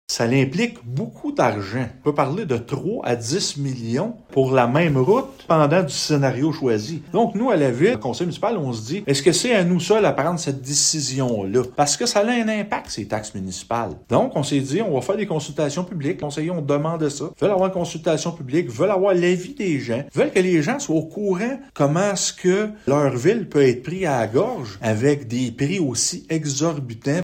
Le maire de Gracefield, Mathieu Caron, explique le processus retenu ainsi que les raisons ayant motivé la Ville à procéder de cette façon :